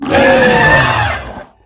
CosmicRageSounds / ogg / general / combat / enemy / baurg / hurt1.ogg
hurt1.ogg